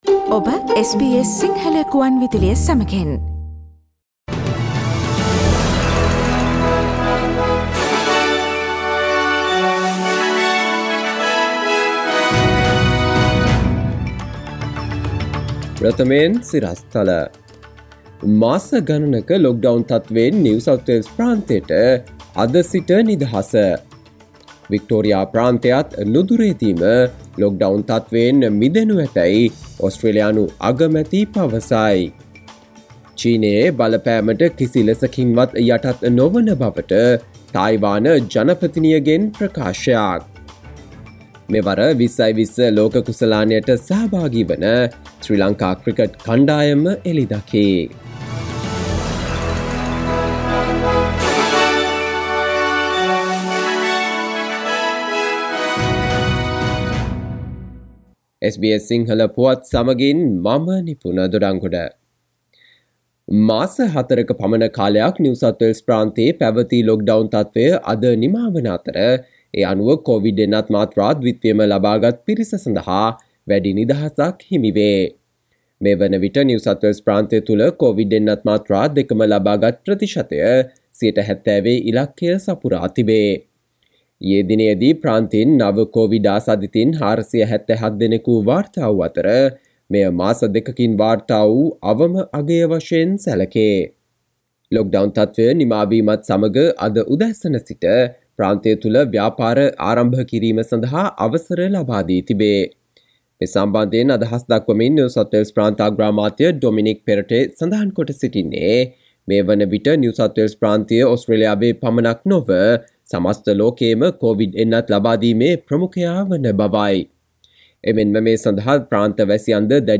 සවන්දෙන්න 2021 ඔක්තෝබර් 11 වන සඳුදා SBS සිංහල ගුවන්විදුලියේ ප්‍රවෘත්ති ප්‍රකාශයට...